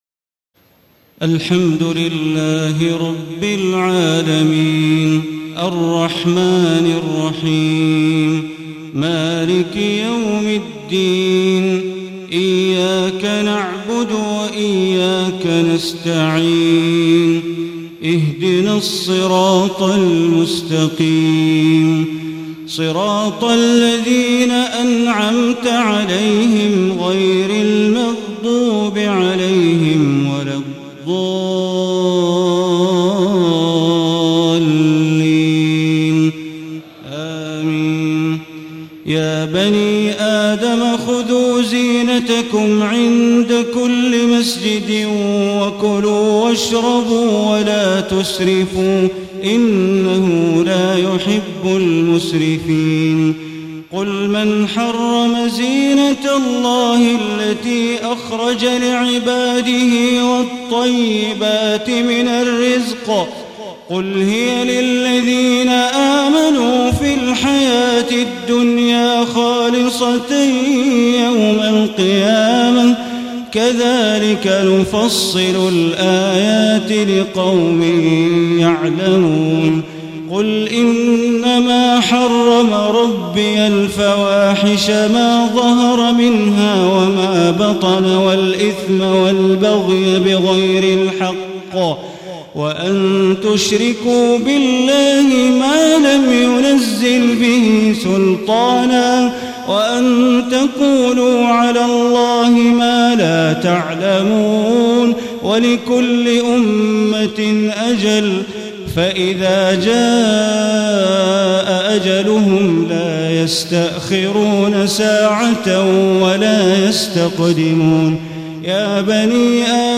تهجد ليلة 28 رمضان 1435هـ من سورة الأعراف (31-93) Tahajjud 28 st night Ramadan 1435H from Surah Al-A’raf > تراويح الحرم المكي عام 1435 🕋 > التراويح - تلاوات الحرمين